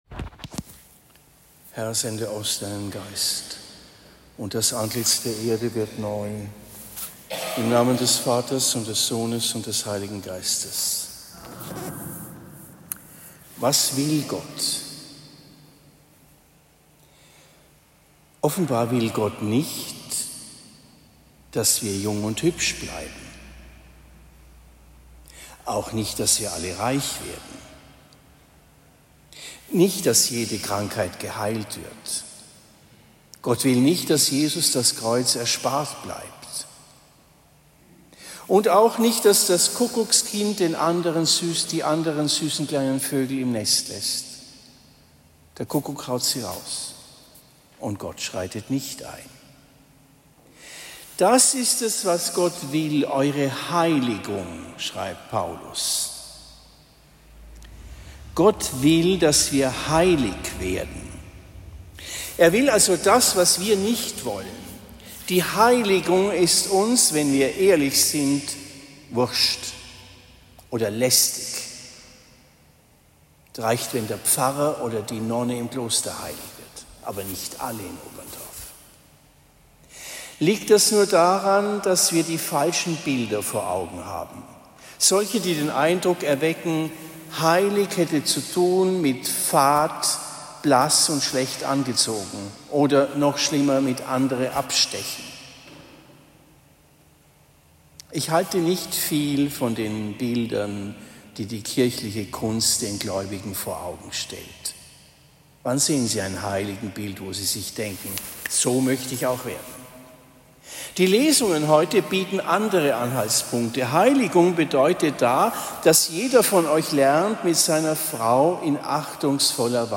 Predigt am 30. August 2024 in Oberndorf Herz-Mariä